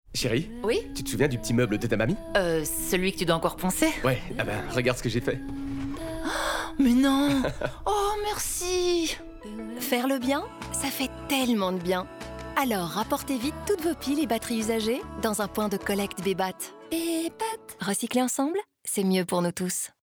Sound Production & Sound Design: La Vita Studios
250324-Bebat-radio-mix-OLA--23LUFS-meuble-FR-20.mp3